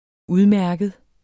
Udtale [ ˈuðˈmæɐ̯gəð ]